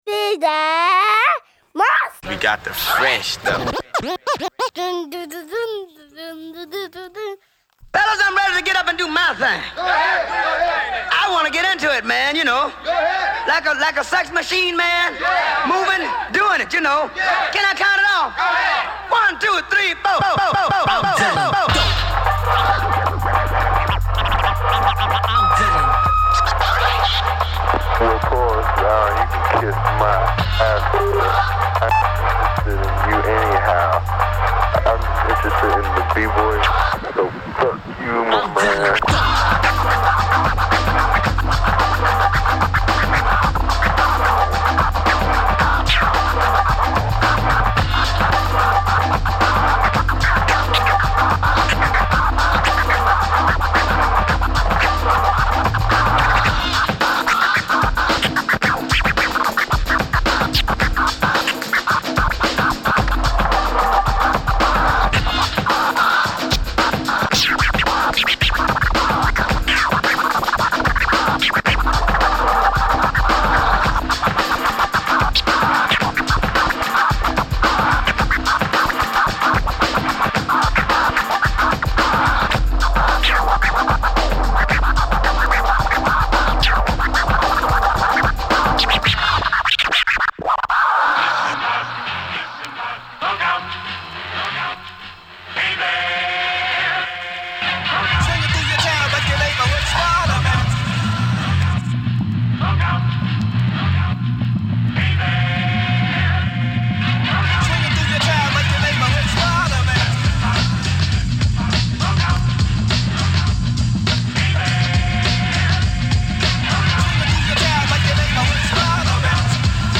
＊試聴はダイジェストです。